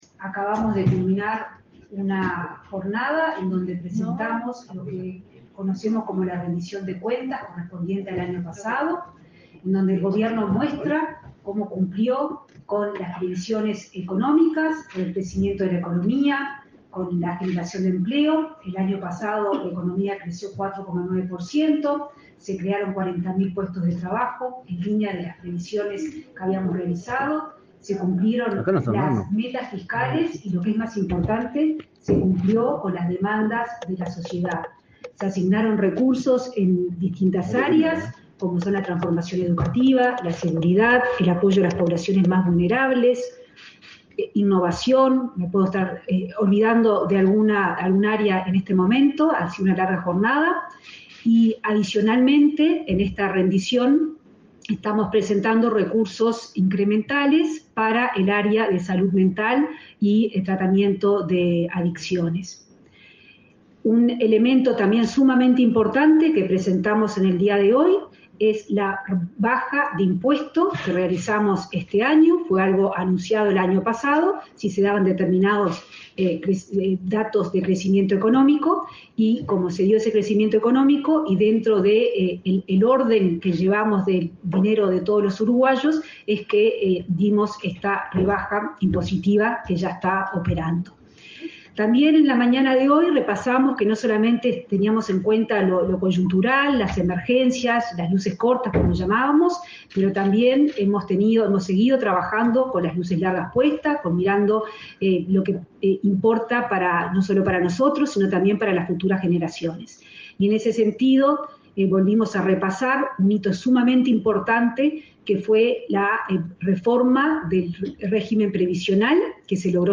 Conferencia de prensa de autoridades del Gobierno tras informar en el Parlamento acerca de la Rendición de Cuentas
Conferencia de prensa de autoridades del Gobierno tras informar en el Parlamento acerca de la Rendición de Cuentas 10/07/2023 Compartir Facebook X Copiar enlace WhatsApp LinkedIn La ministra de Economía y Finanzas, Azucena Arbeleche, informó, este 10 de julio, a la Comisión de Presupuesto integrada con Hacienda de la Cámara de Representantes acerca de la Rendición de Cuentas. Tras la reunión, la citada jerarca y el presidente del Banco Central, Diego Labat, respondieron preguntas de la prensa.